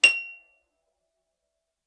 KSHarp_F7_f.wav